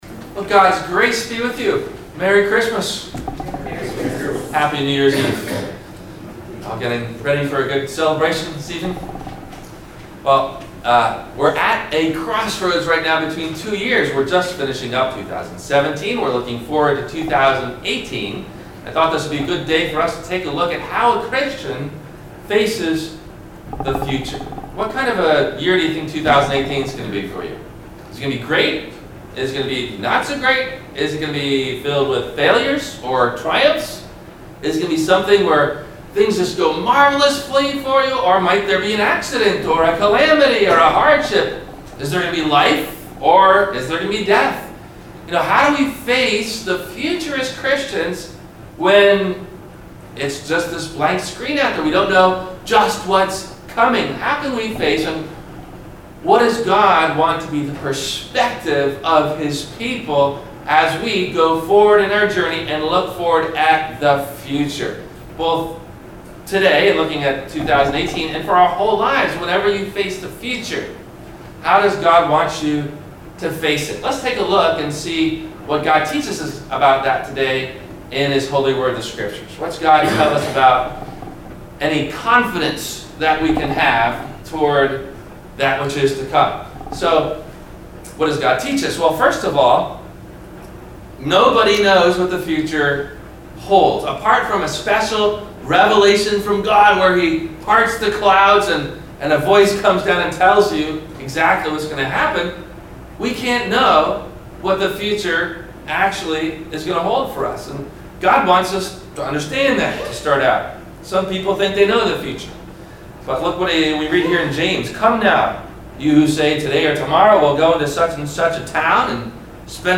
How To Face the Future with Confidence - Sermon - December 31 2017 - Christ Lutheran Cape Canaveral
This was the Sunday morning New Years Eve service.